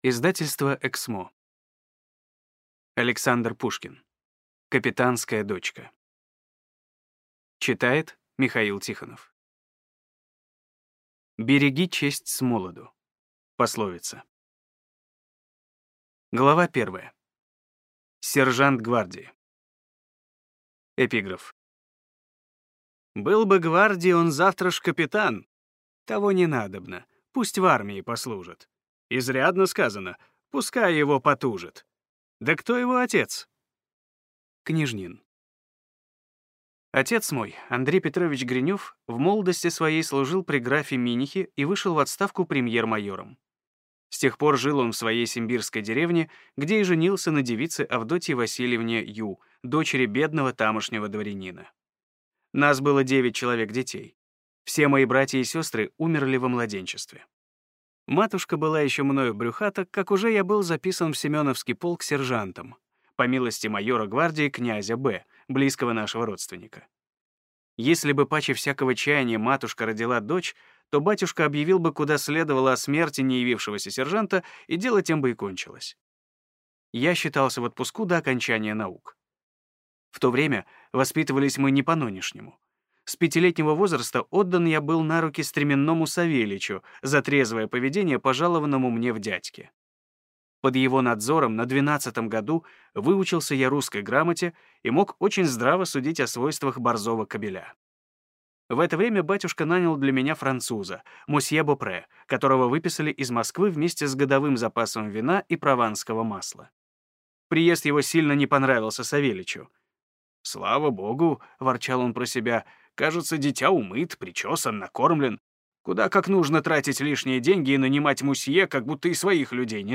Аудиокнига Капитанская дочка | Библиотека аудиокниг